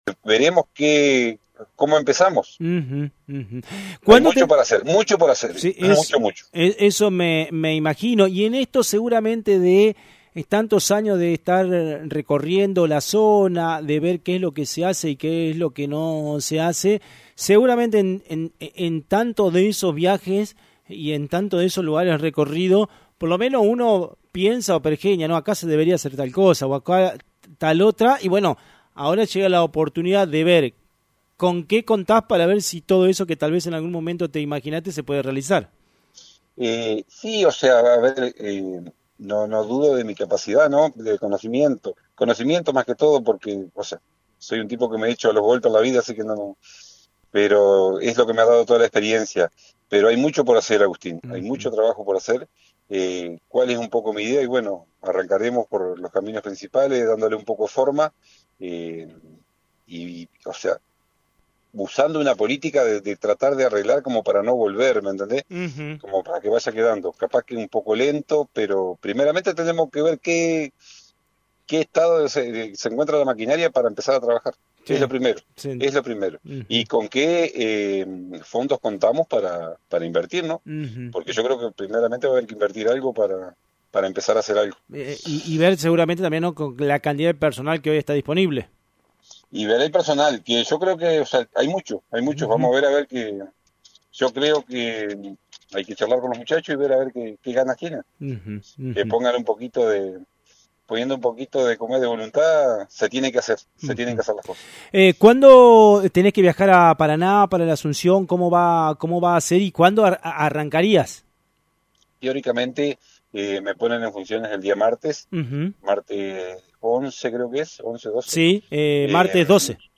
En diálogo con FM 90.3, expresó que su idea es comenzar por los caminos principales y “arreglar para no volver”, haciendo alusión a mejorar la calidad de los trabajos. Prevé realizar un exausitivo análisis del estado de la maquinaria, y lo más difícil, con qué fondos contará para invertir.